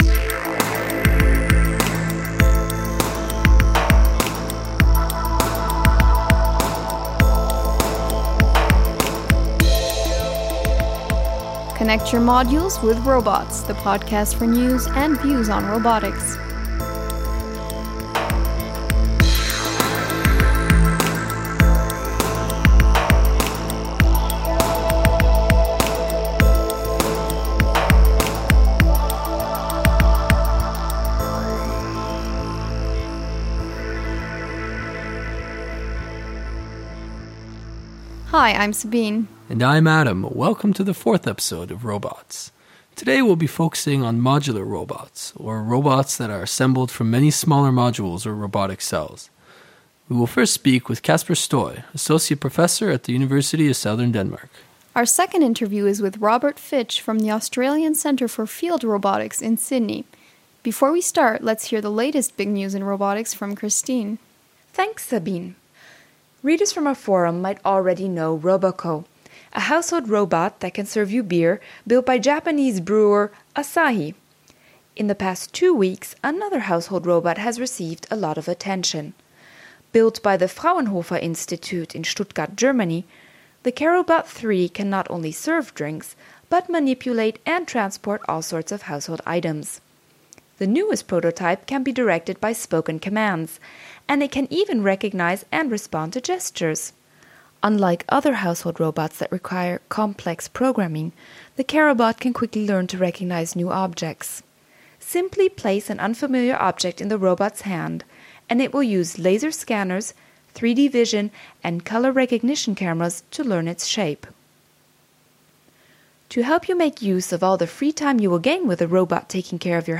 In today’s episode we focus on modular robotics, or robots assembled out of many smaller modules. Whether all the modules are the same (‘homogeneous’) or of different types (‘heterogeneous’), modular robots can accomplish many different tasks simply by adjusting their configuration. We speak with two experts in the field